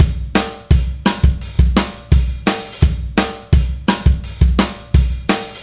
Indie Pop Beat 04.wav